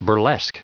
added pronounciation and merriam webster audio
124_burlesque.ogg